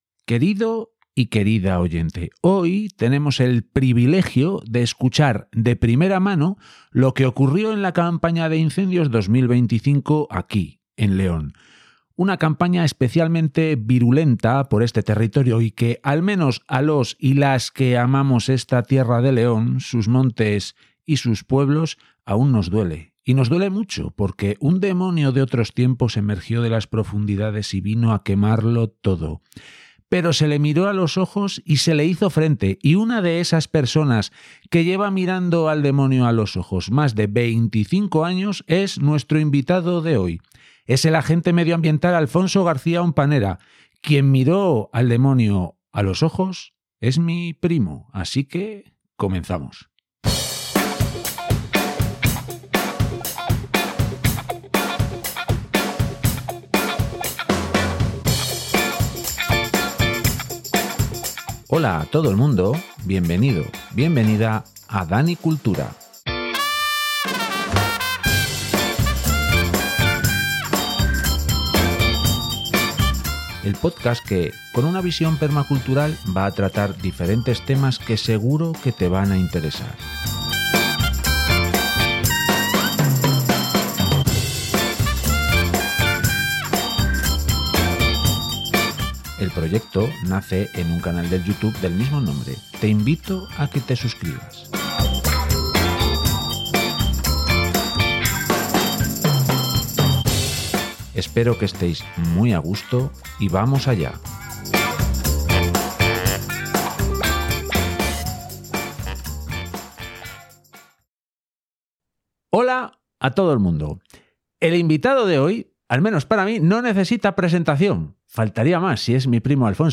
Uno es que tengo el privilegio de entrevistar a un referente en lo que a incendios forestales se refiere.